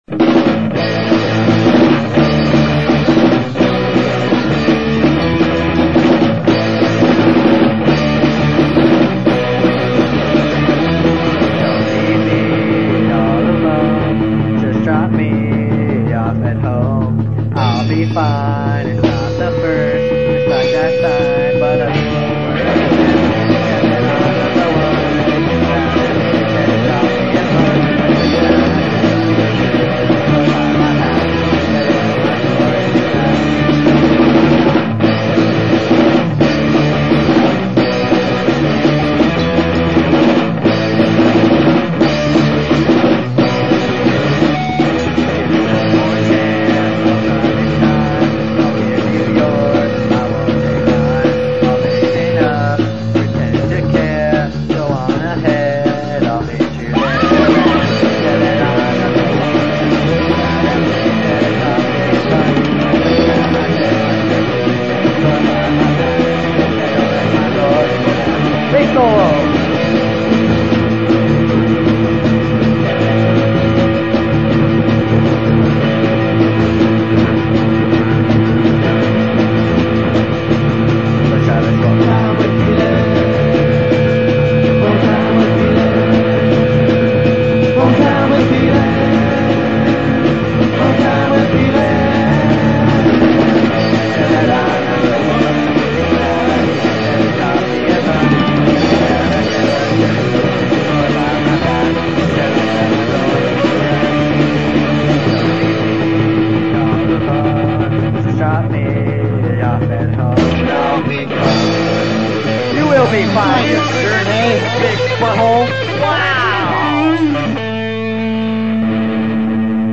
Guitar
Drums
Bass/Vocals